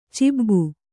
♪ cibbu